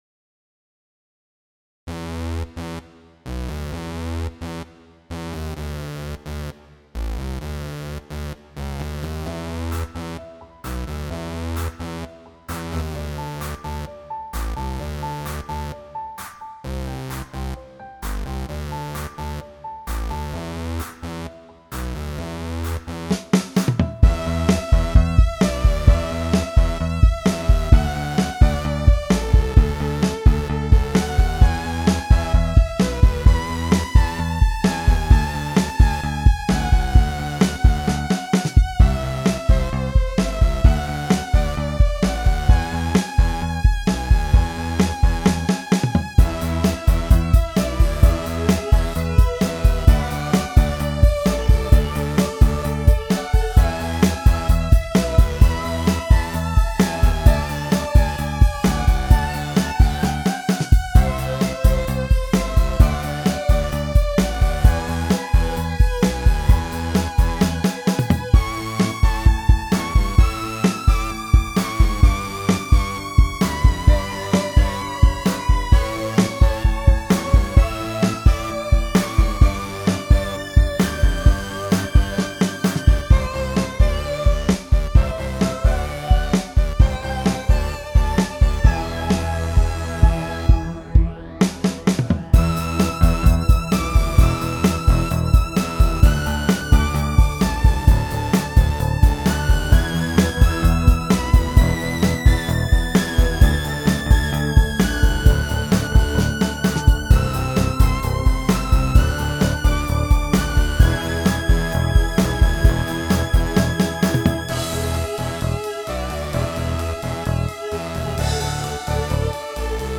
The main string sound on that album is the Vox Humana preset from the old Polymoog. I acquired samples of that voice and decided to make a song with them: not a cover of one of the existing songs, but a new song which sounds like it could be a "lost track" from the same recording sessions.